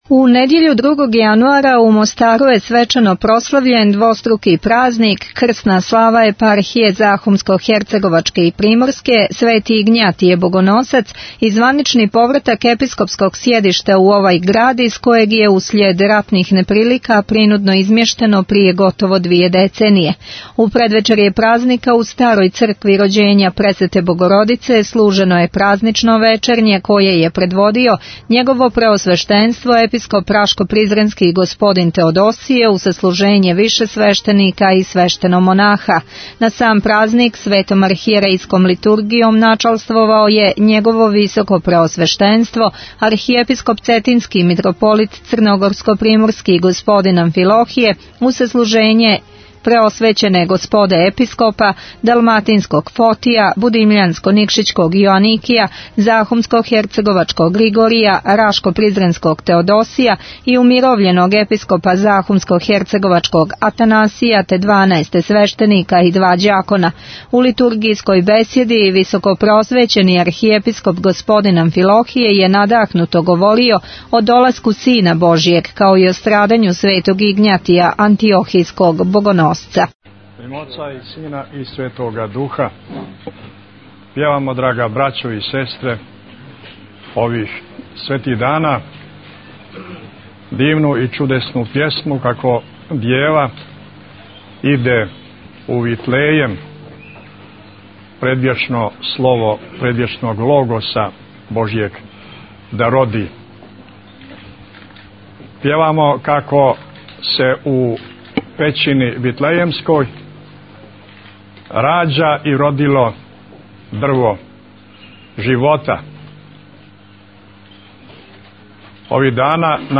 На сам празник Светом архијерејском литургијом началствовао је Његово Високопреосвештенство Архиепископ Цетињски Митрополит Црногорско - приморски Г. Амфилохије, уз саслужење Преосвећене Господе Епископа: Далматинског Фотија, Будимљанско - никшићког Јоаникија, Захумско - херцеговачког Григорија, Рашко - призренског Теодосија и умировљеног Епископа Захумско - херцеговачког Атанасија, те дванаест свештеника и два ђакона.